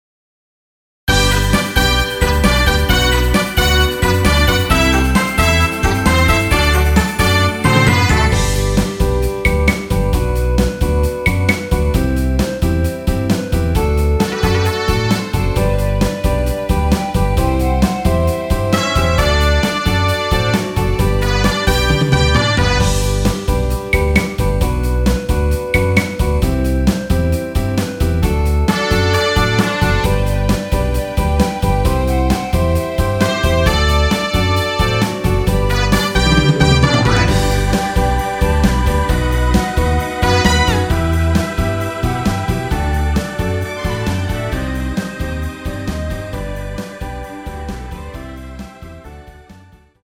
원키에서(+2)올린 멜로디 포함된 MR입니다.
앞부분30초, 뒷부분30초씩 편집해서 올려 드리고 있습니다.
중간에 음이 끈어지고 다시 나오는 이유는